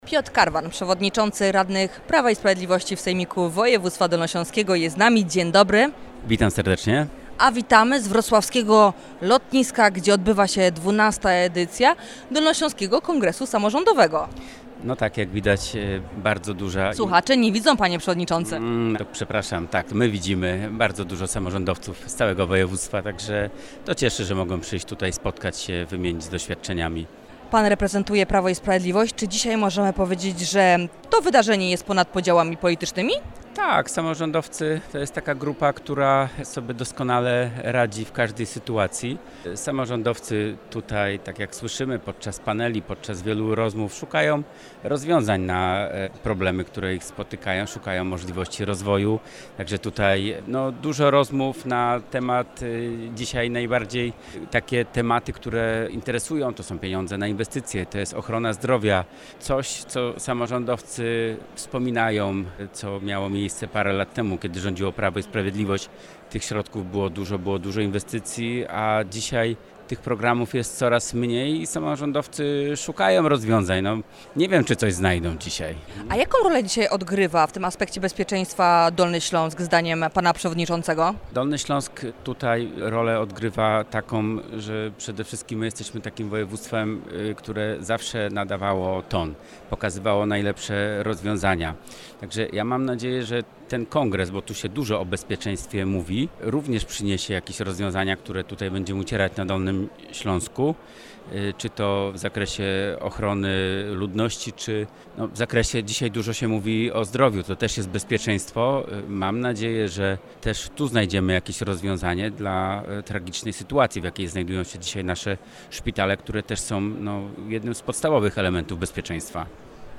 Piotrem Karwanem, przewodniczącym radnych PiS w Sejmiku Województwa Dolnośląskiego: